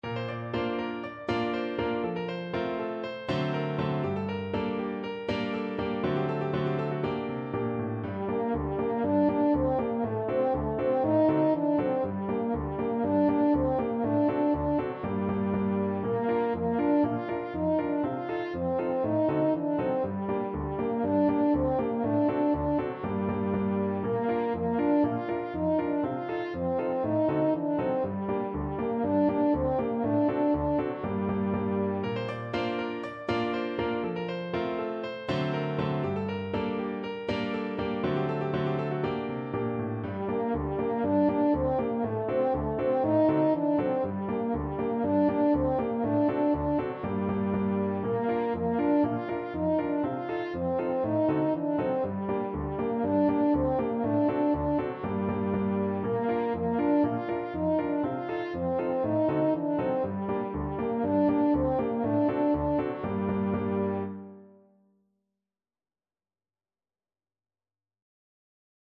4/4 (View more 4/4 Music)
Allegro (View more music marked Allegro)
Traditional (View more Traditional French Horn Music)
Ukrainian